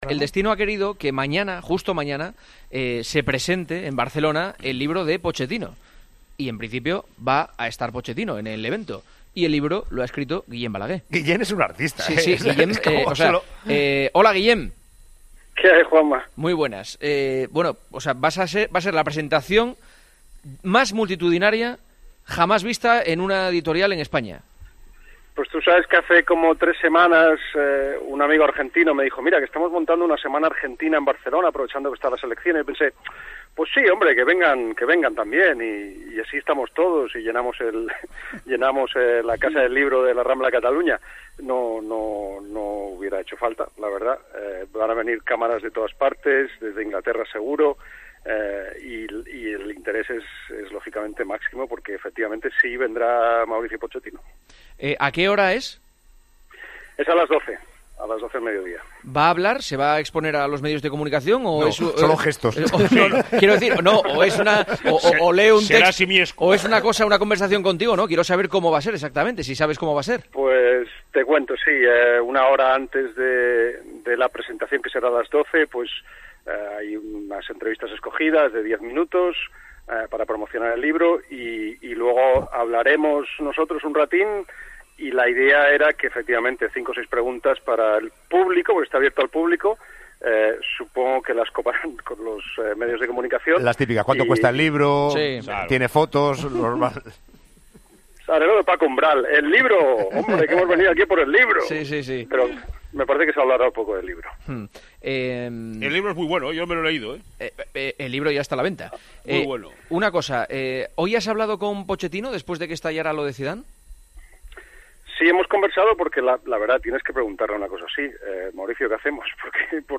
Con el actual entrenador del Tottenham como principal favorito en todas las quinielas para suceder a Zinedine Zidane en el banquillo del Real Madrid preguntamos al periodista catalán cómo ve el posible fichaje.